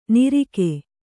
♪ nirike